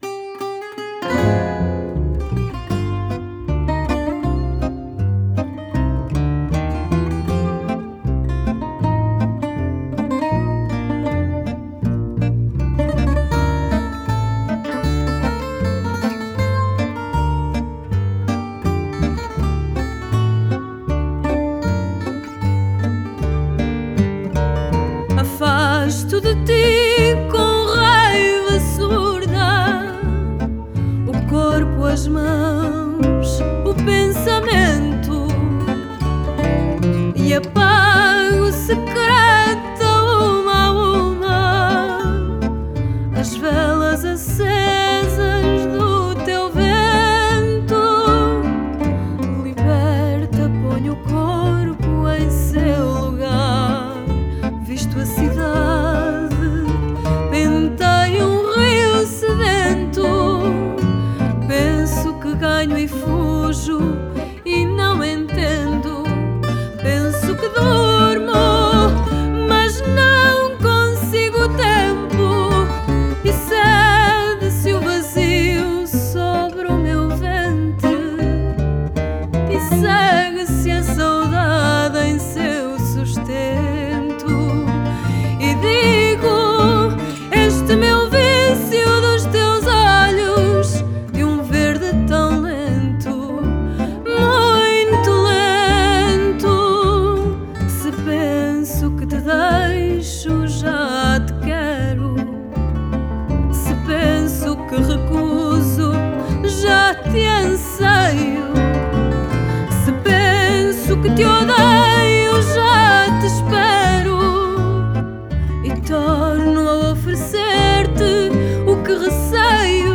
Style: Fado